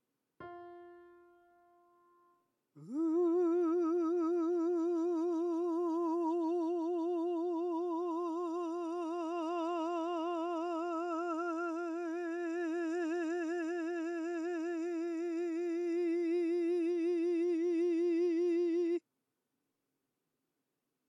パターン3. 5つの母音を動きをつけて繋げる
音量注意！